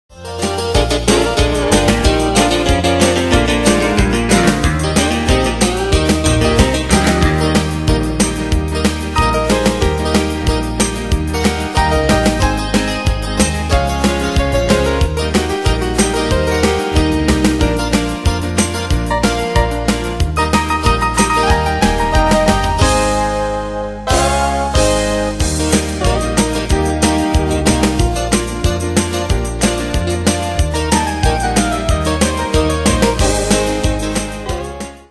Style: Country Année/Year: 2000 Tempo: 186 Durée/Time: 3.16
Danse/Dance: Rock Cat Id.
Pro Backing Tracks